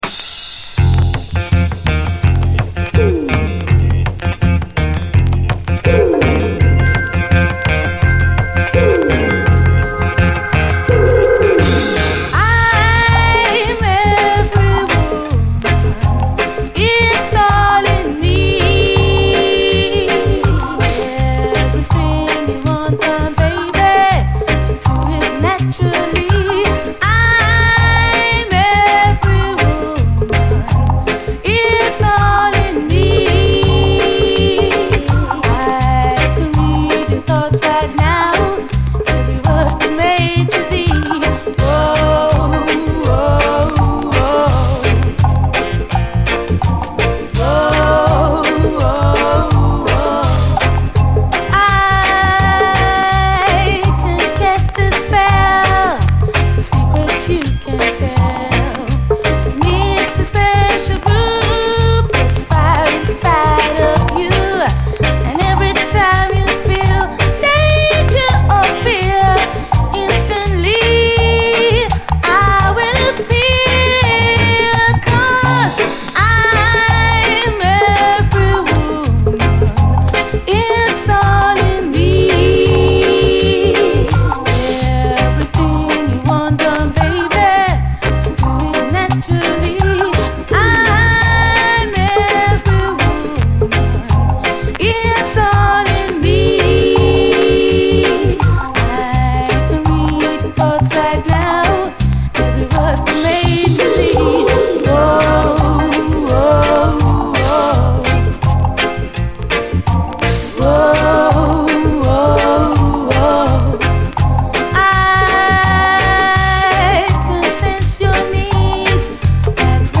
サックスやフルート、オーボエなど複数の管楽器を一度に口にくわえて同時に演奏してしまう
ソウルもジャズも黒人霊歌も垣根なく飲み込んだ間口の広いユニティー感、溢れるヒューマニティーこそが魅力！